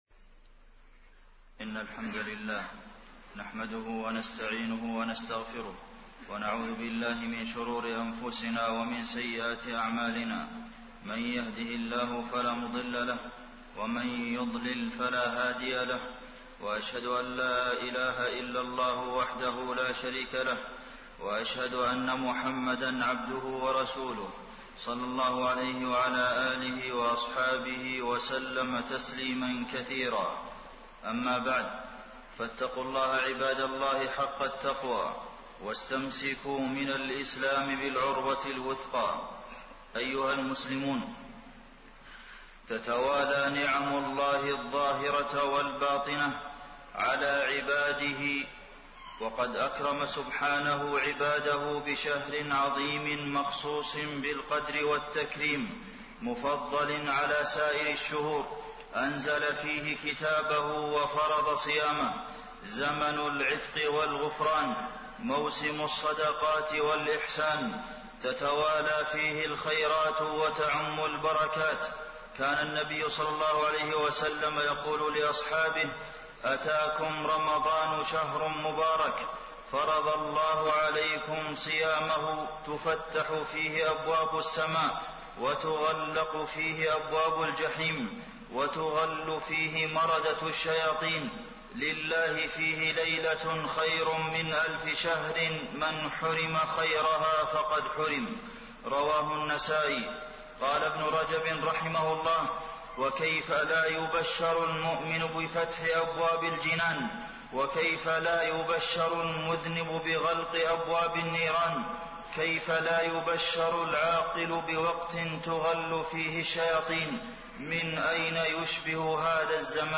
تاريخ النشر ١٢ رمضان ١٤٣٢ هـ المكان: المسجد النبوي الشيخ: فضيلة الشيخ د. عبدالمحسن بن محمد القاسم فضيلة الشيخ د. عبدالمحسن بن محمد القاسم رمضان شهر التوبة والإنابة